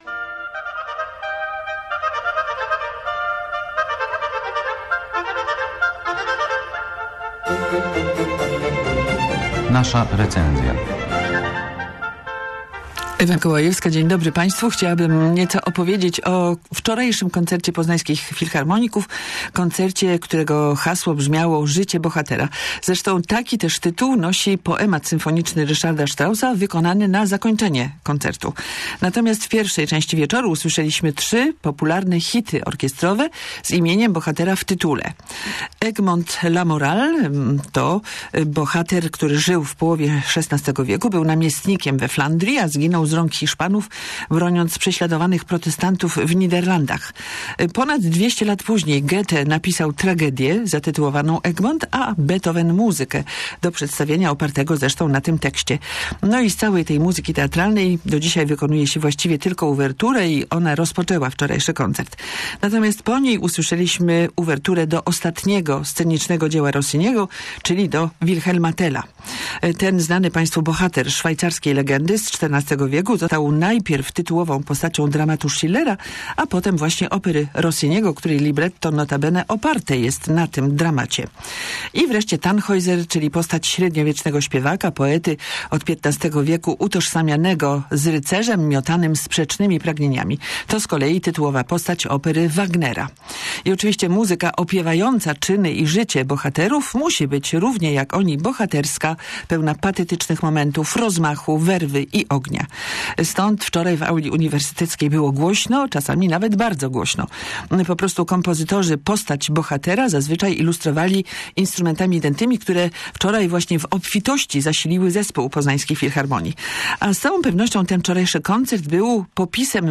19u8djs1nhr6eog_zycie_bohatera_recenzja_koncertu.mp3